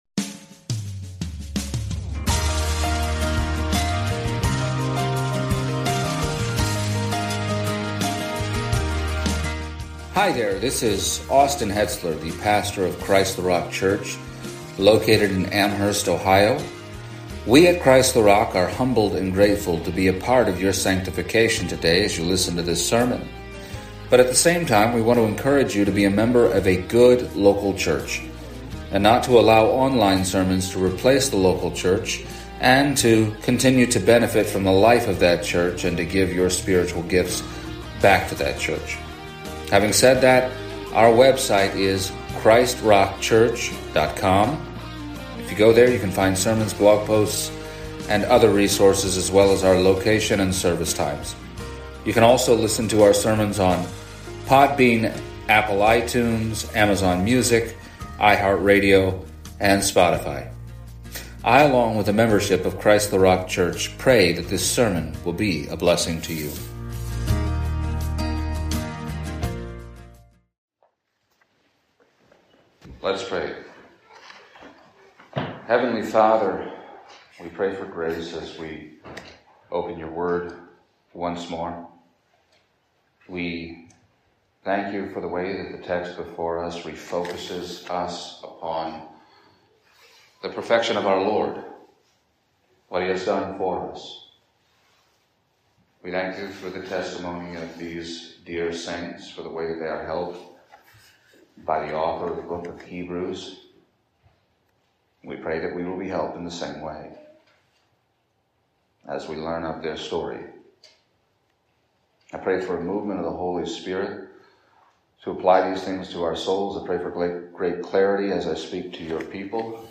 Exposition of the Epistle to the Hebrews Passage: Hebrews 3:1-2 Service Type: Sunday Morning “Behavior modification is